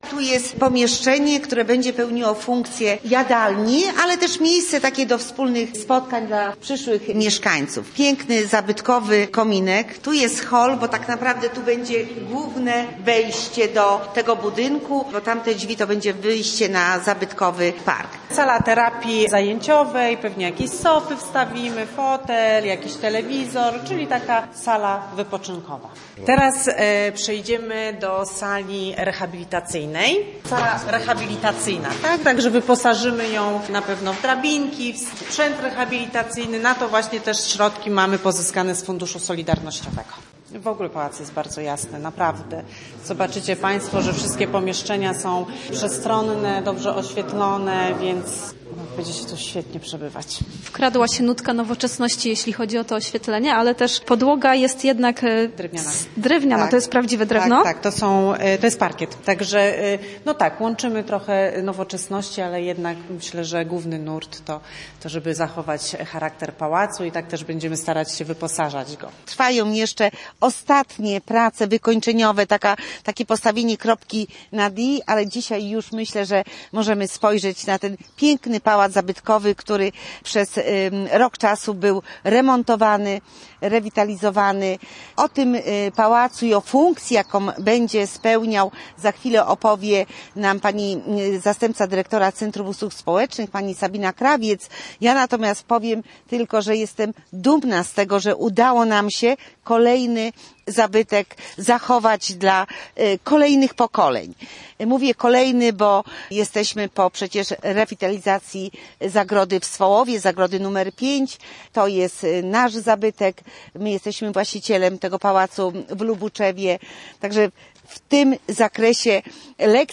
Posłuchaj materiału naszej reporterki: